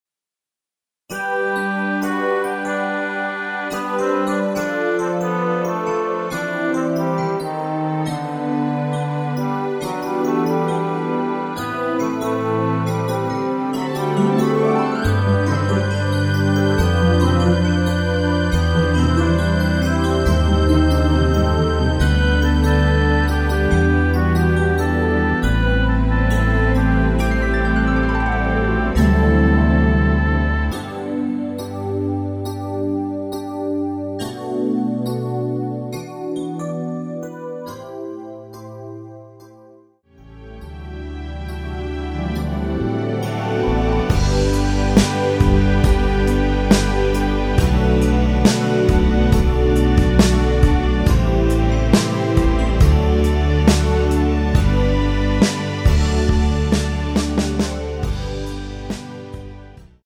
음 깔끔하고 고급스러워서 축가로 쓰려고 합니다~ 감사합니다.
앞부분30초, 뒷부분30초씩 편집해서 올려 드리고 있습니다.